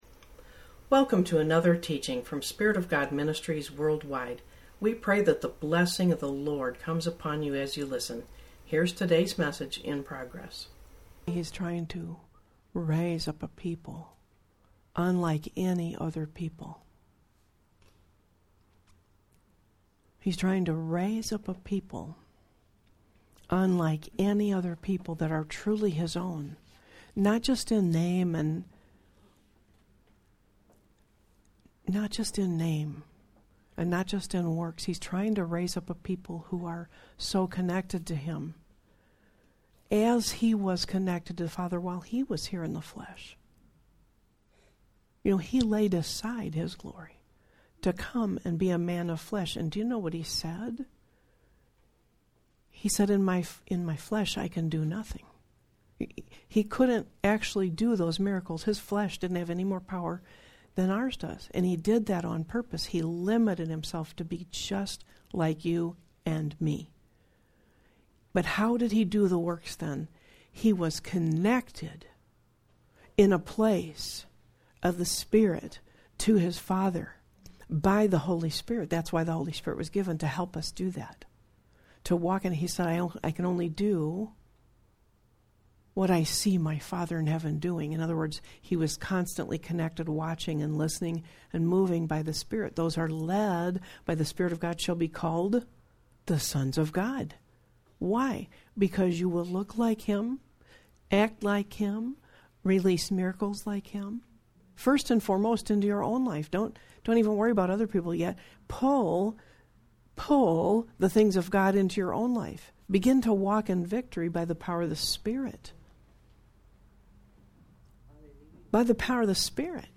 Sermons | Spirit Of God Ministries WorldWide
House meeting in Wood Dale